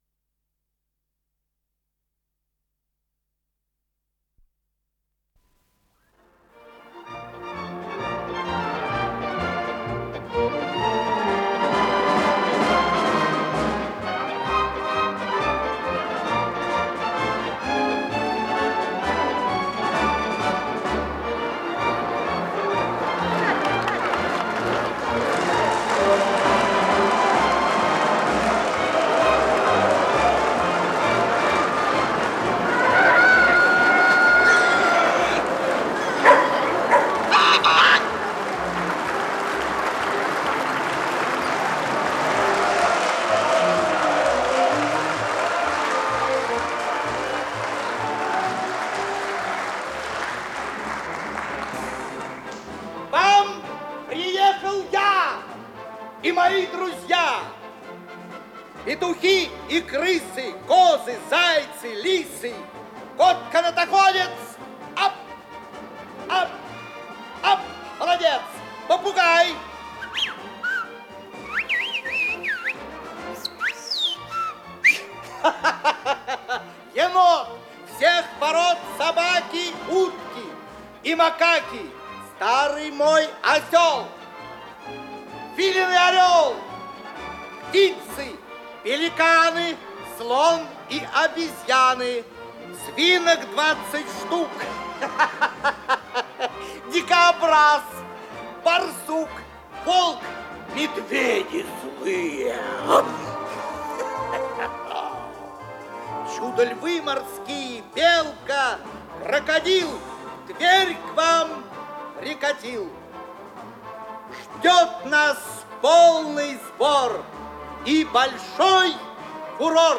Инсценированные страницы книги